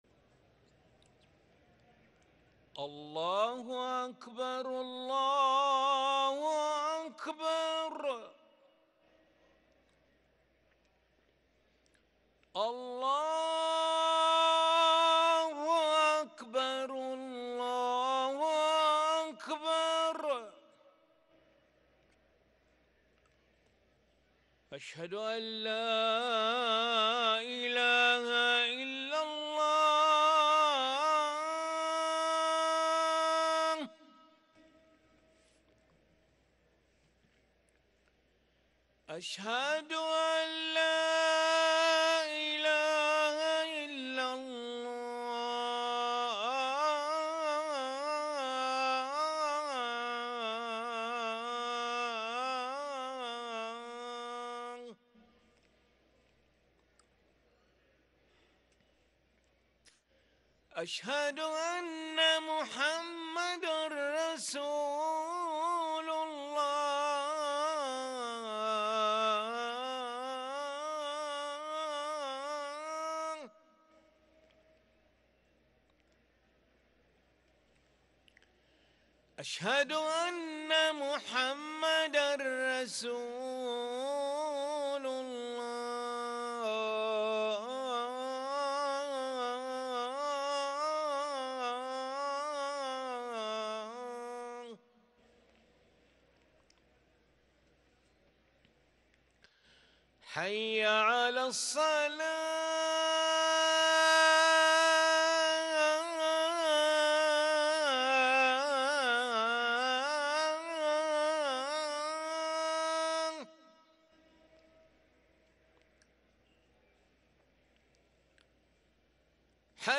أذان العشاء للمؤذن علي ملا الخميس 5 جمادى الآخرة 1444هـ > ١٤٤٤ 🕋 > ركن الأذان 🕋 > المزيد - تلاوات الحرمين